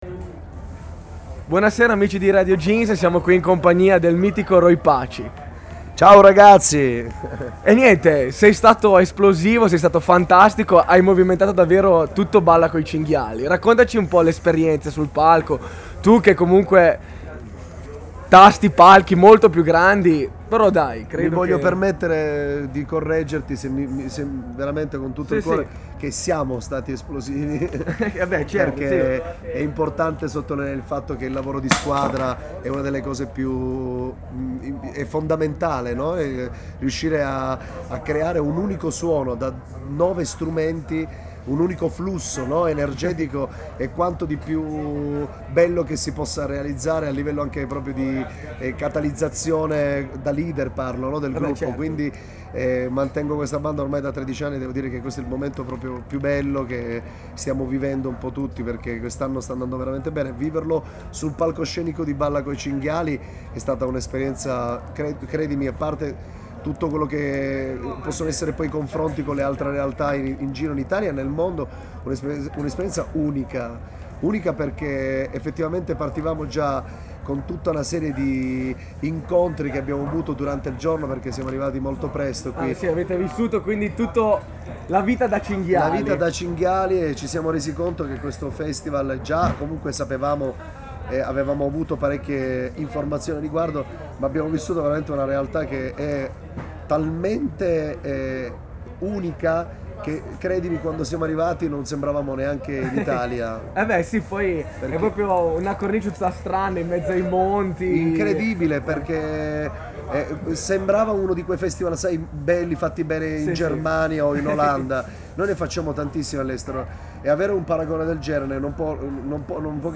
Intervista a Roy Paci al Balla coi Cinghiali
Intervista a Roy Paci a Bardineto, dopo il suo concerto al Balla Coi Cingiali